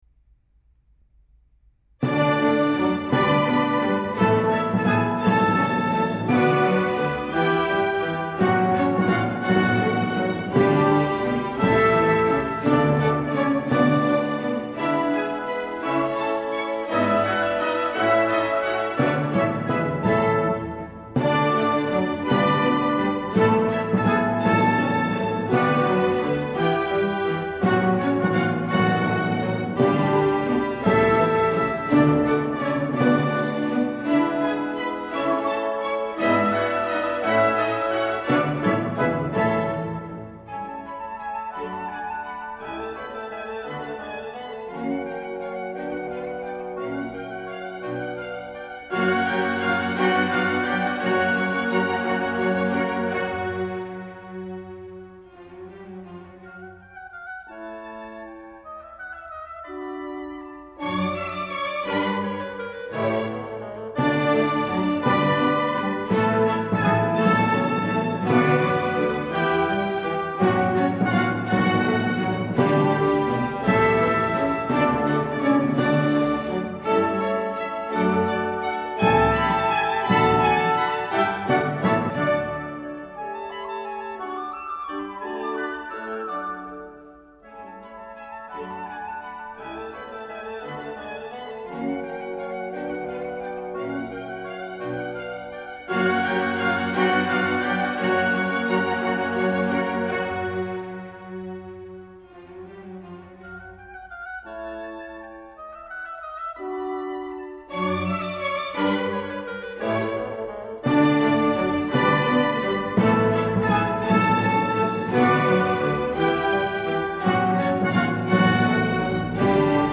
音樂類型：古典音樂
生意盎然，活力十足。